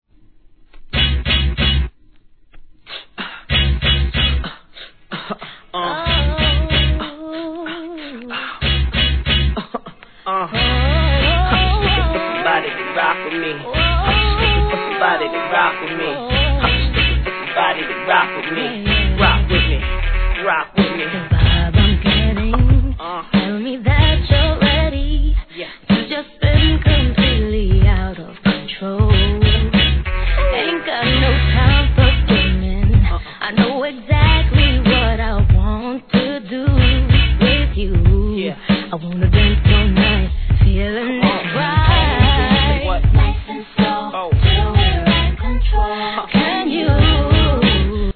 HIP HOP/R&B
メロディアスなシンセ使いが心地良いトラックに絶妙なコーラスワークが冴え渡る